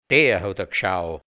Mundart-Wörter | Mundart-Lexikon | hianzisch-deutsch | Redewendungen | Dialekt | Burgenland | Mundart-Suche: G Seite: 15